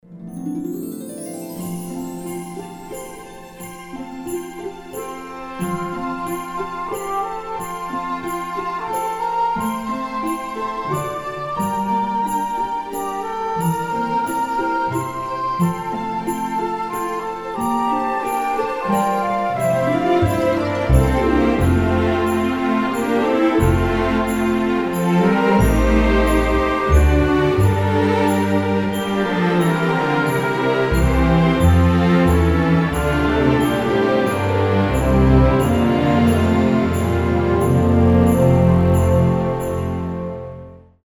• Качество: 320, Stereo
спокойные
без слов
красивая мелодия
нарастающие
добрые
колокольчики
рождественские
Чудесная рождественская музыка